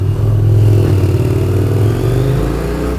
In deze pilot meten we geluidsoverlast met zes sensoren op het Marineterrein.
Verkeer (mp3)
vehicle.mp3